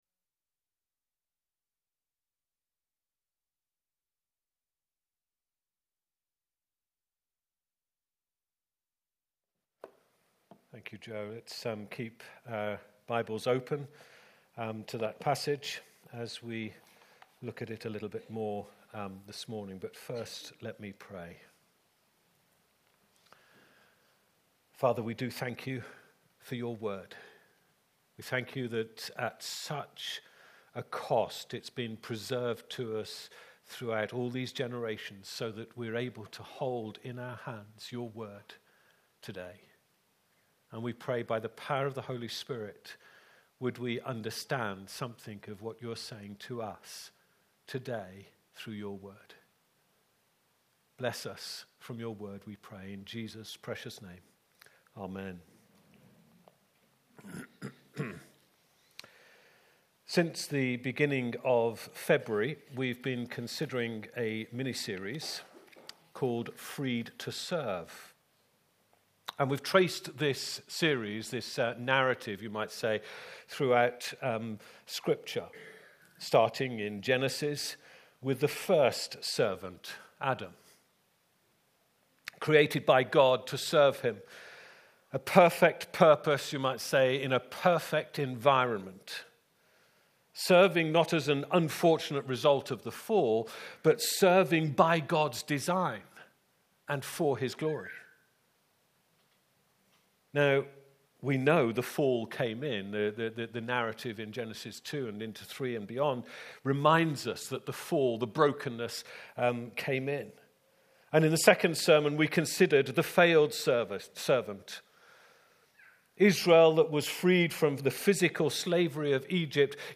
Sermons | ChristChurch Banstead